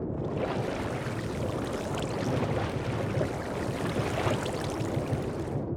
Minecraft Version Minecraft Version 25w18a Latest Release | Latest Snapshot 25w18a / assets / minecraft / sounds / minecart / inside_underwater2.ogg Compare With Compare With Latest Release | Latest Snapshot
inside_underwater2.ogg